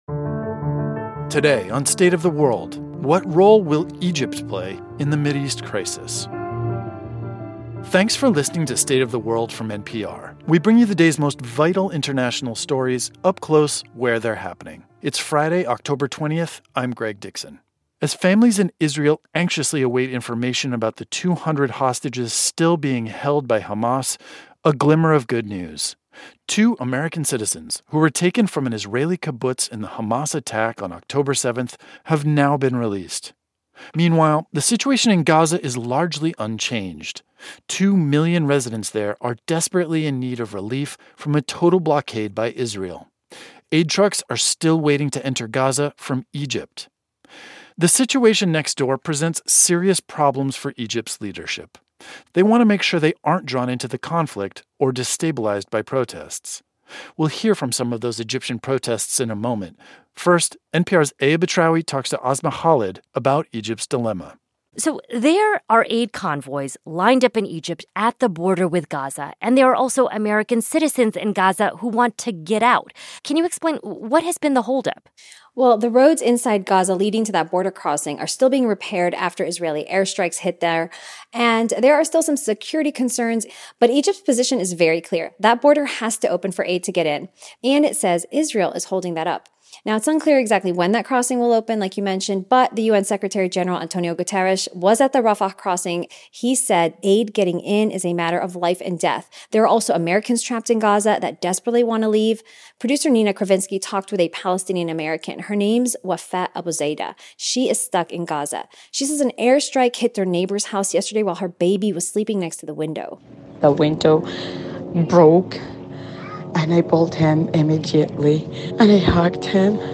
They want to make sure aid gets into suffering residents of Gaza but they don't want to accept refugees for fear of being drawn into the conflict. We hear analysis of how Egypt's leadership is responding and the voices of protesters in Cairo.